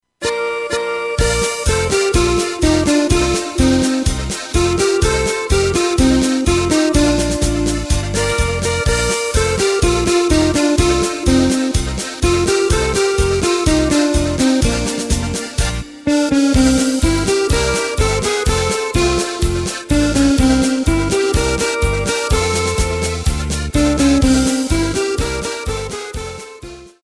Takt:          2/4
Tempo:         125.00
Tonart:            Bb
Wiener-Lied!